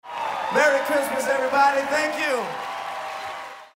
S – MERRY CHRISTMAS EVERYONE – SHOUT
S-MERRY-CHRISTMAS-EVERYONE-SHOUT.mp3